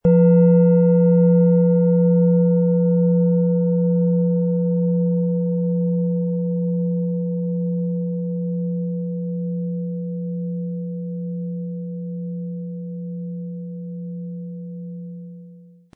Es ist eine von Hand getriebene Klangschale, aus einer traditionellen Manufaktur.
• Mittlerer Ton: Mond
PlanetentöneChiron & Mond
SchalenformBihar
MaterialBronze